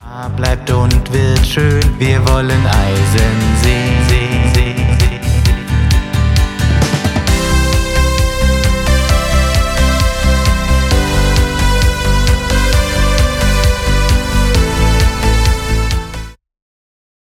resonanzen entfernen
und noch ein paar eq manipulationen...